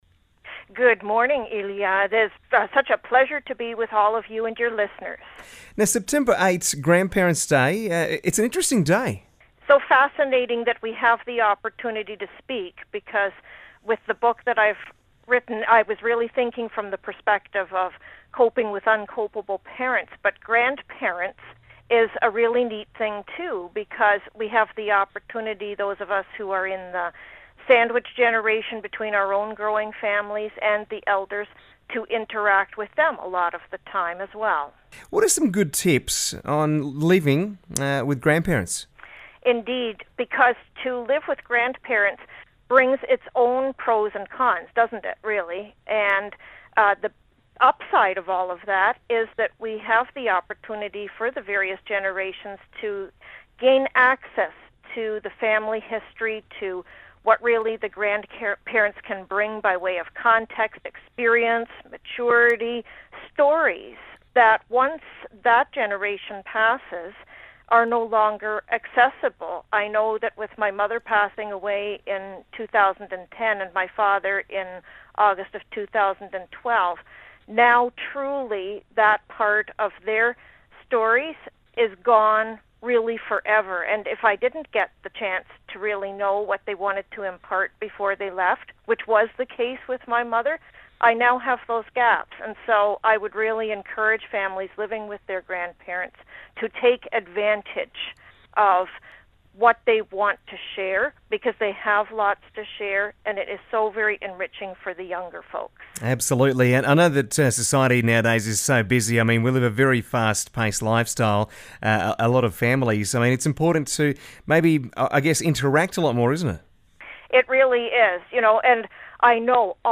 Toginet Interview